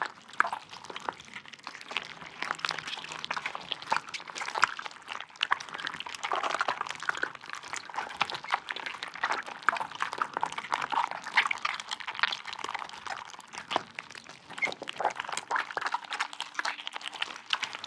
Sfx_creature_seamonkeybaby_idle_close_to_hatch_squishy_loop_01.ogg